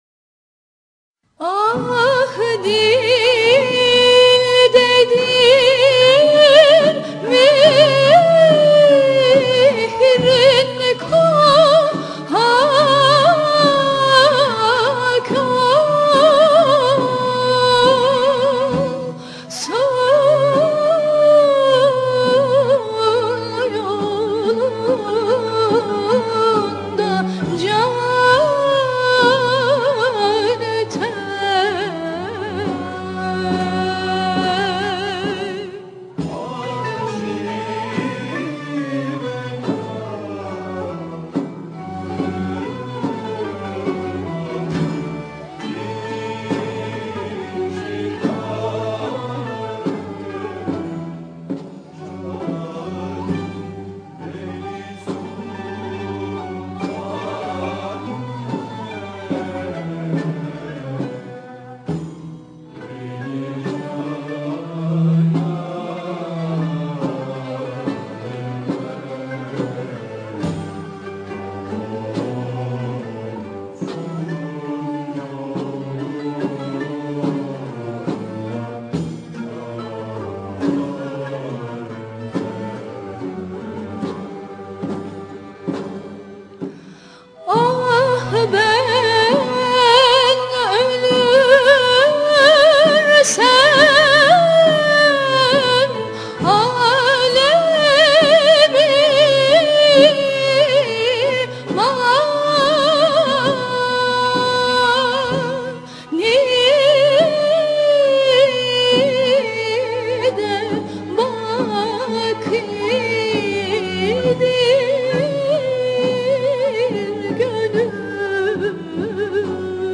Usûl: Lenk Fahte